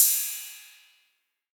808CY_6_Tape_ST.wav